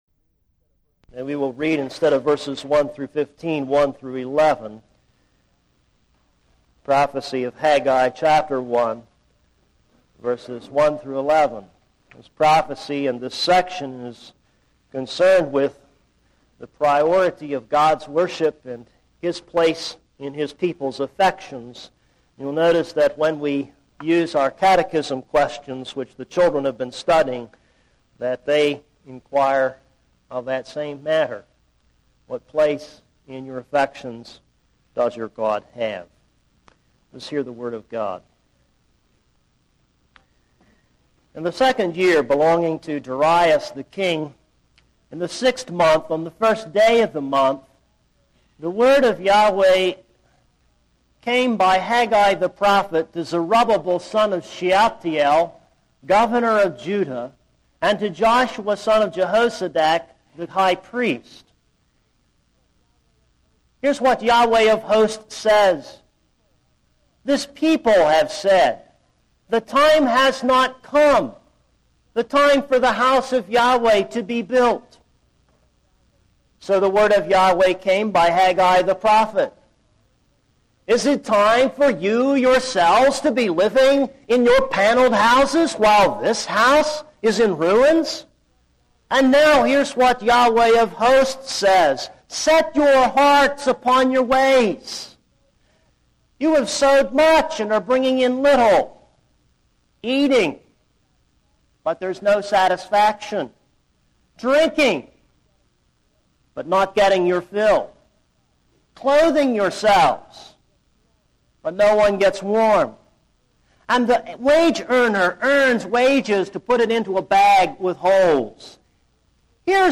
This is a sermon on Haggai 1:1-11.